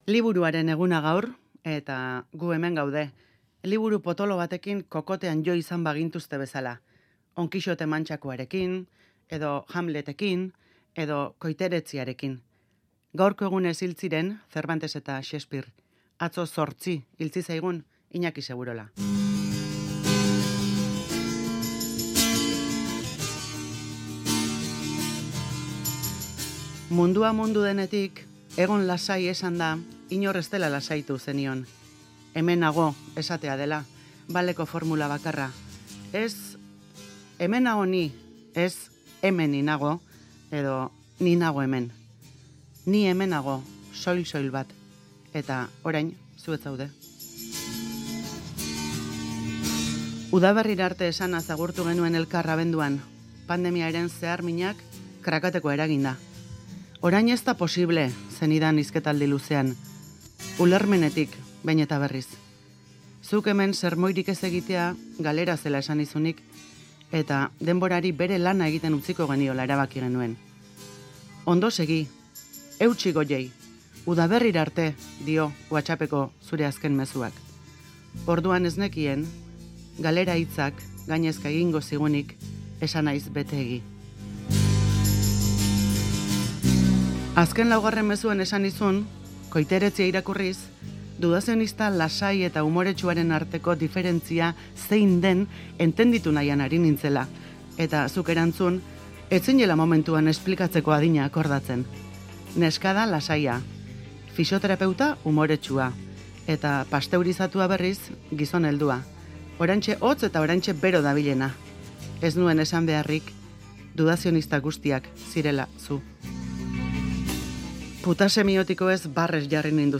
sermoiska